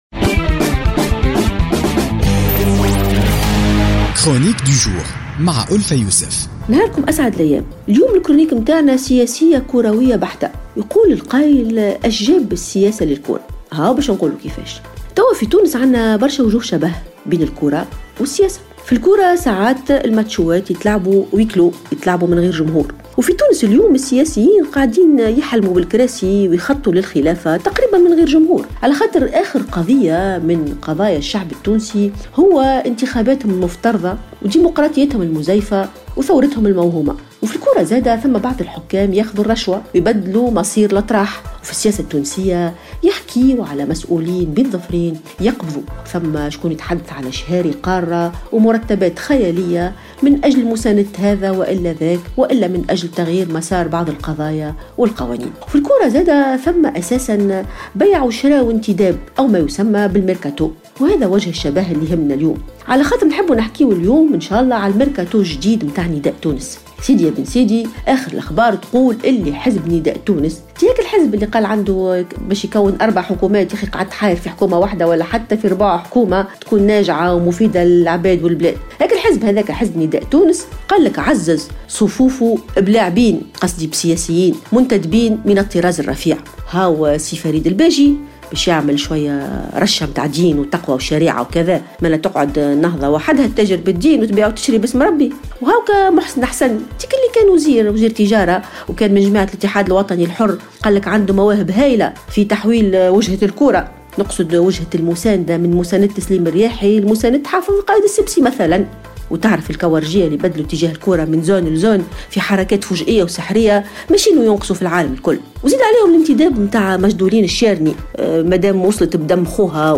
تطرقت الكاتبة ألفة يوسف في افتتاحية اليوم الثلاثاء 21 مارس 2017 إلى وجه الشبه الكبير بين السياسة والرياضة في تونس ومنطق كرة القدم التي تلعب فيها بعض المباريات دون جمهور .